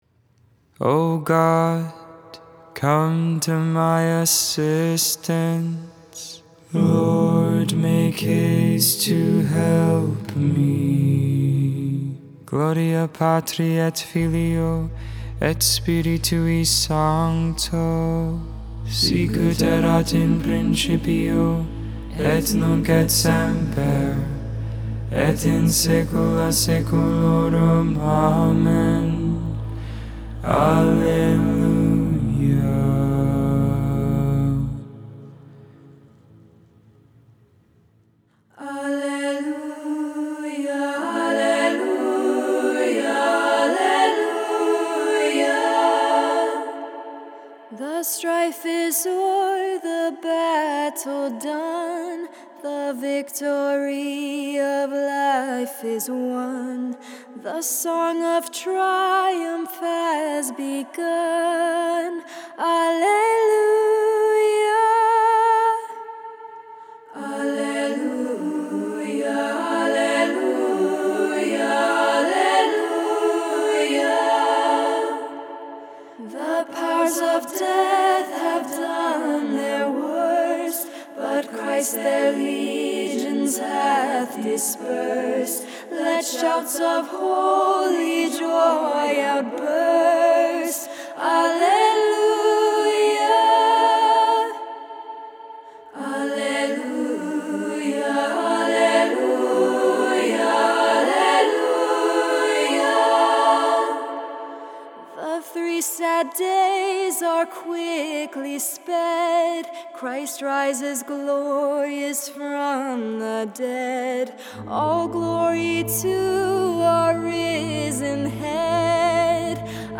4.24.22 Vespers, Sunday Evening Prayer
Vespers, Evening Prayer for Divine Mercy Sunday, April 24th, 2022.